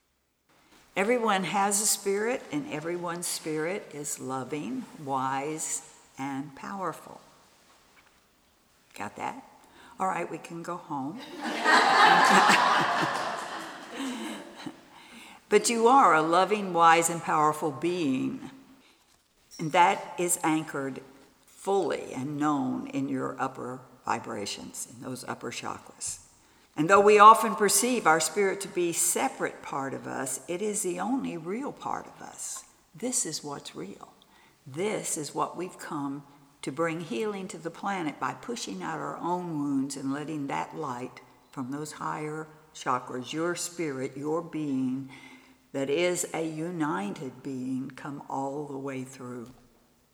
Spirit’s Messages – Workshop
Workshop Recordings